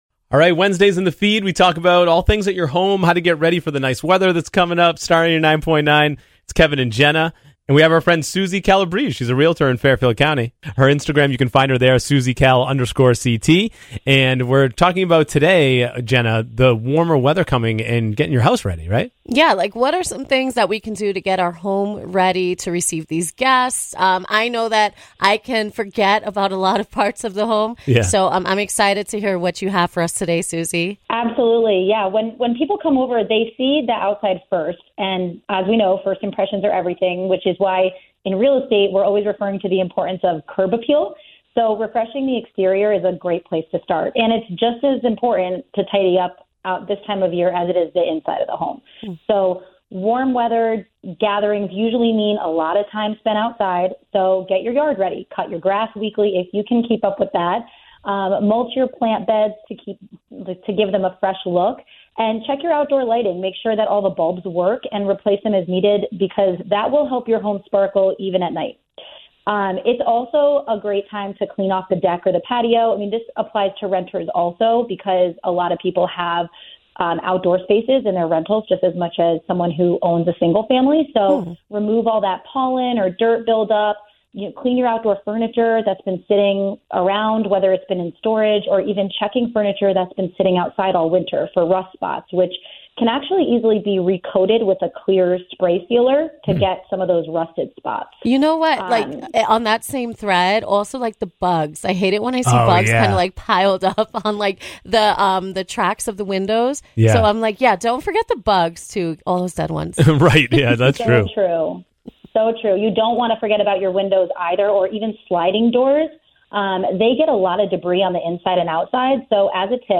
chat with a local expert about the things that make our lives easier, from home improvement and DIY’s to parenting hacks.